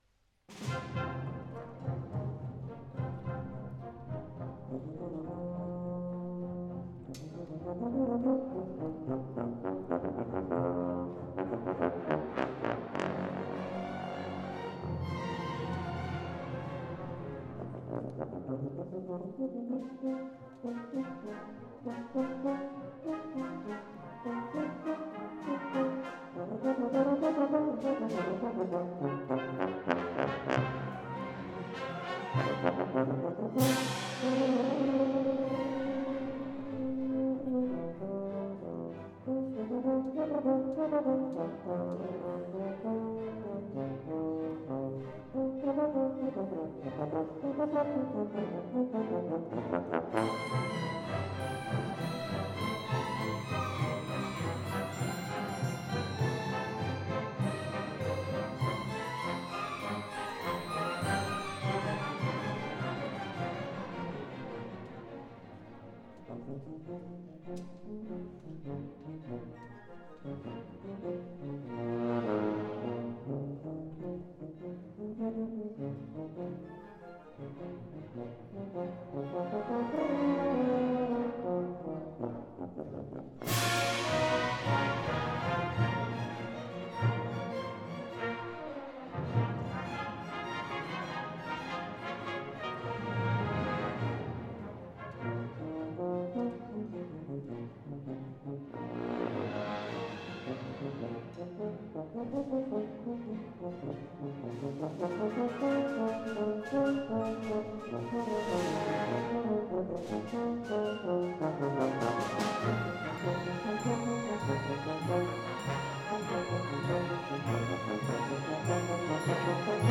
Beethoven and Bernstein, Kansas State University Symphony Orchestra Concert